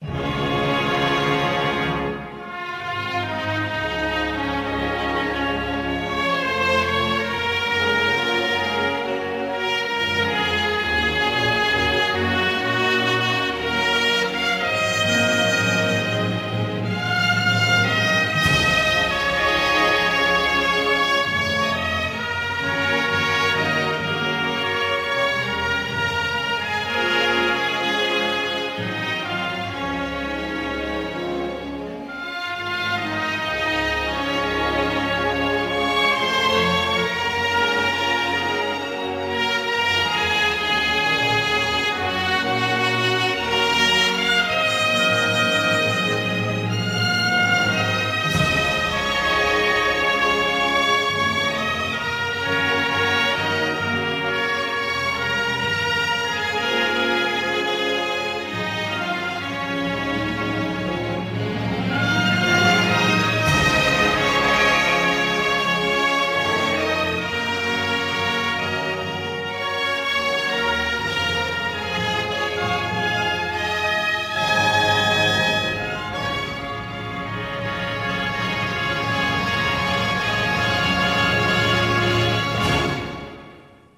в сокращенной оркестровой версии